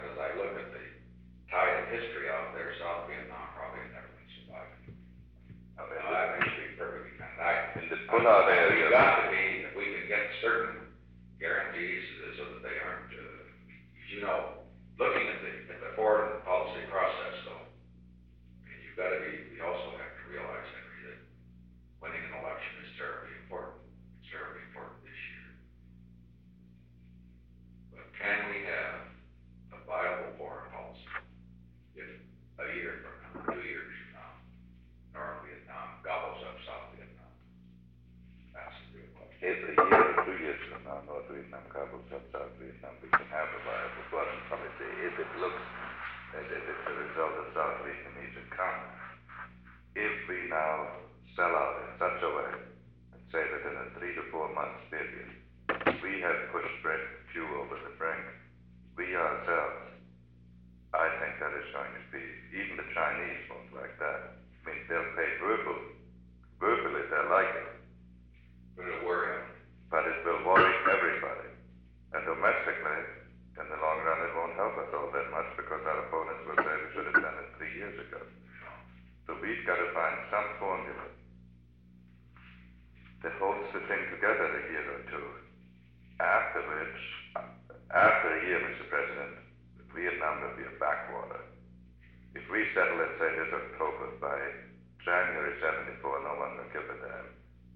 Date: August 3, 1972 Location: Oval Office Tape Number: 760-006 Participants Richard M. Nixon Henry A. Kissinger Associated Resources Annotated Transcript Audio File Transcript